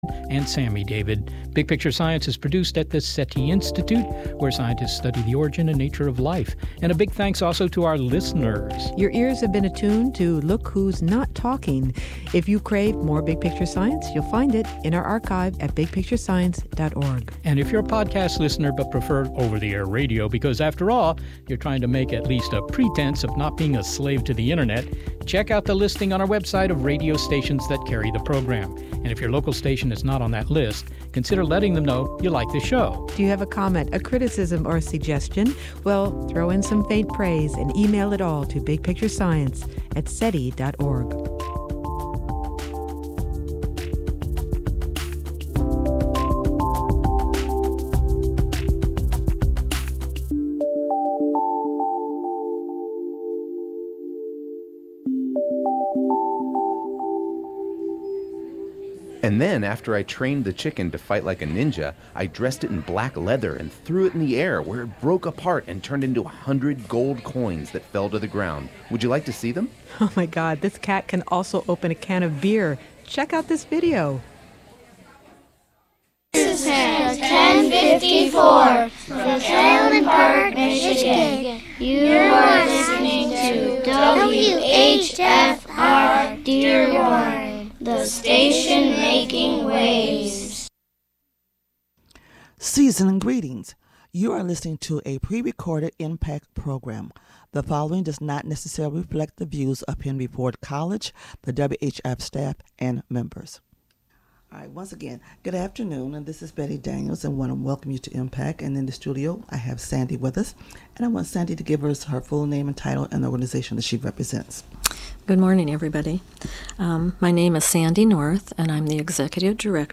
stops in to share heartfelt acoustic guitar and vocals.